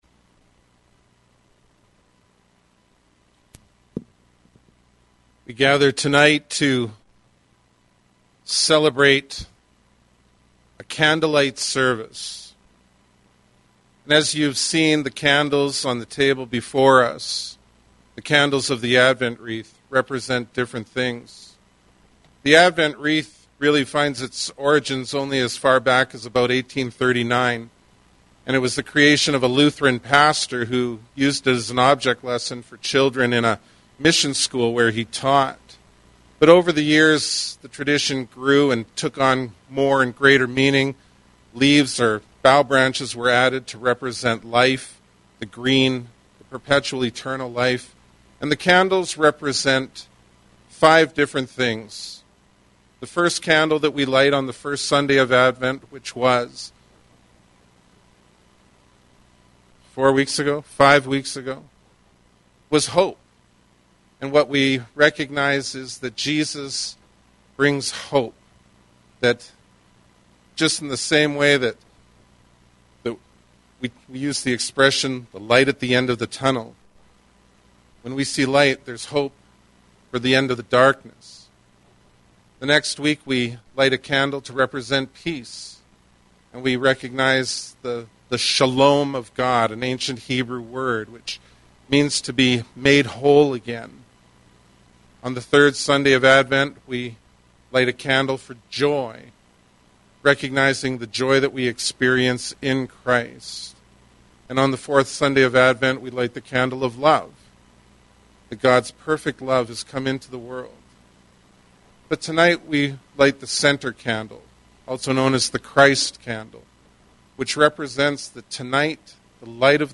Dec-24-Sermon.mp3